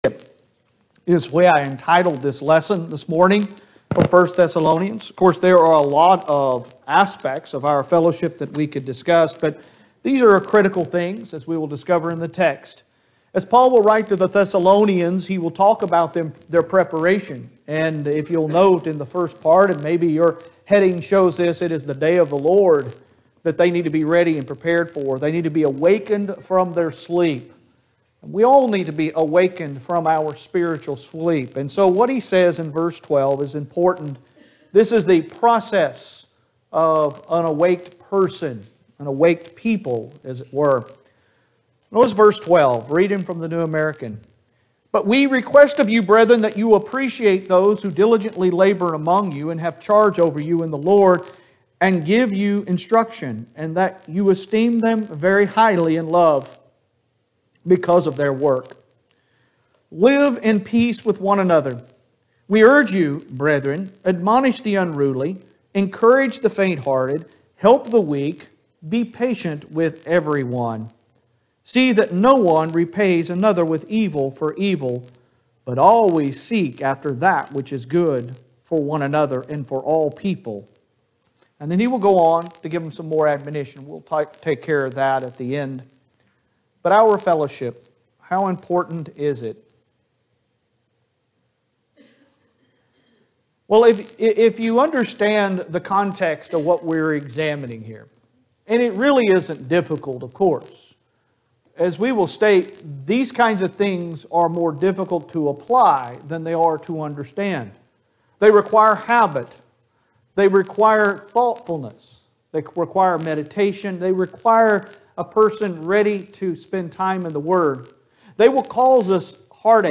Sun AM Sermon- 10.31.21